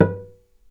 vc_pz-B4-mf.AIF